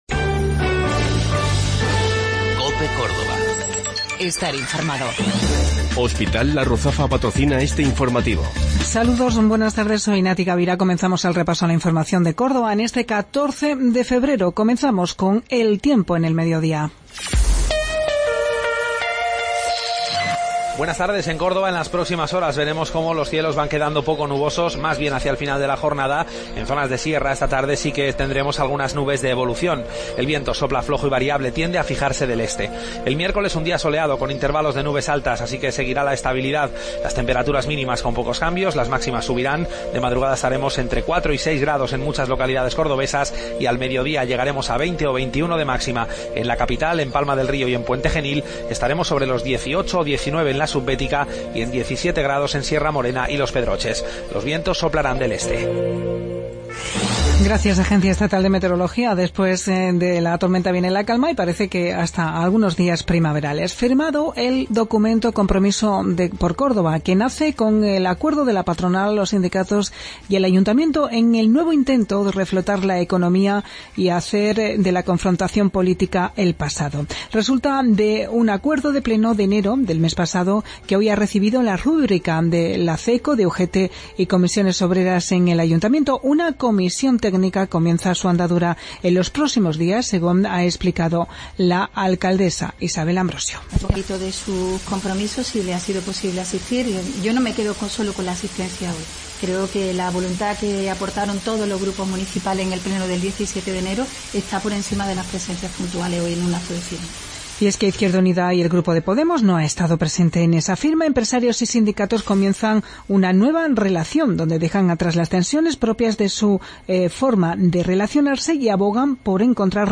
Mediodía en Cope. Informativo local 14 de Febrero 2017